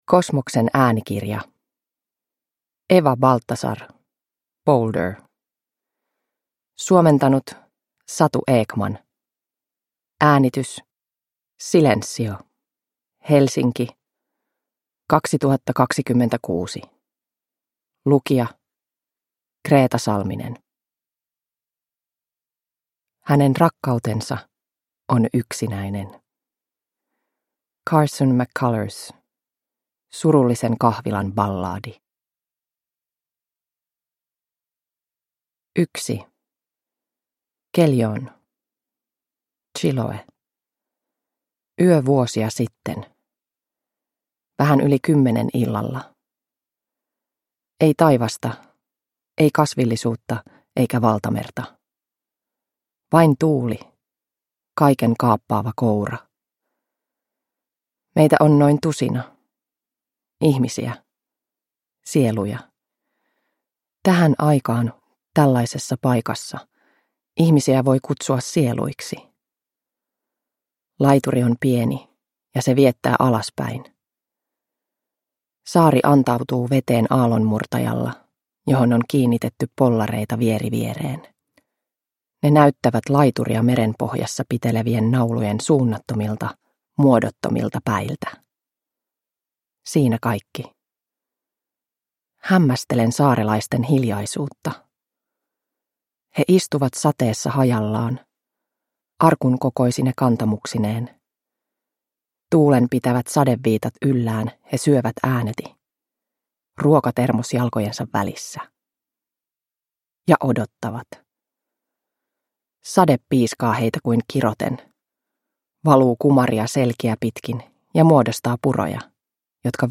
Boulder – Ljudbok